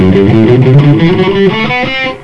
Ok, here is the tab, just a 12 note ascending run in E Natural Minor from the F note.
All down's